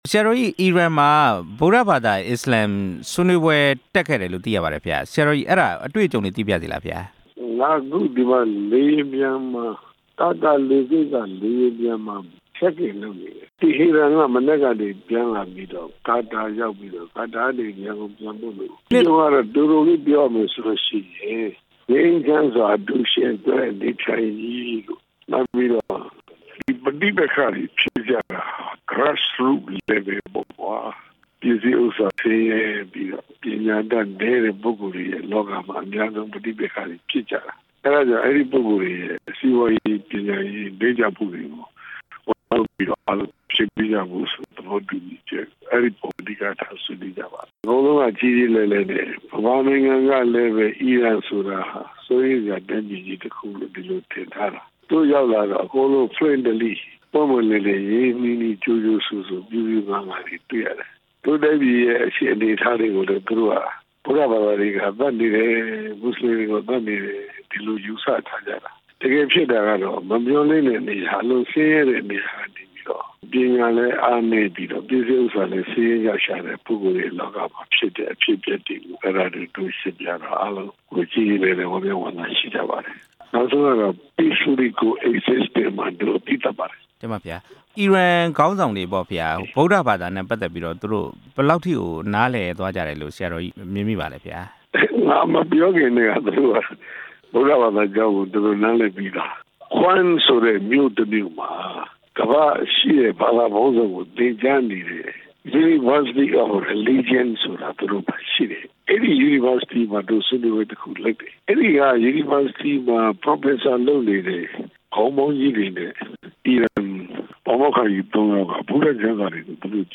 (RFA က ဆက်သွယ်လျှောက်ထားချိန်မှာ ဆရာတော်ကြီးဟာ အီရန်နိုင်ငံက ပြန်လည်ကြွချီလာပြီး ကာတာနိုင်ငံ ဒိုဟာမြို့ အပြည်ပြည်ဆိုင်ရာလေဆိပ်ကို ရောက်ရှိနေပြီဖြစ်ပါတယ်။)